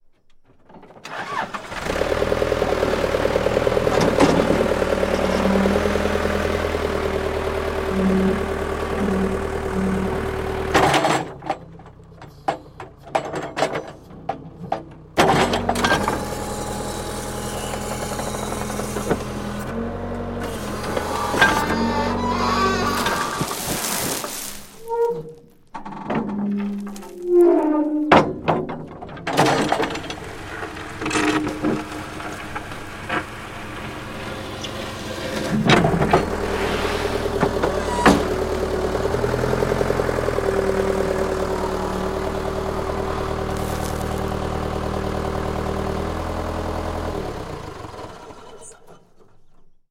音效-34个铲斗启动金属铲斗吱吱作响污垢刮擦无损音效-学驰资源
音效介绍34个拖拉机启动、金属铲斗和吱吱作响、液压延伸和缩回、污垢刮擦等无损音效.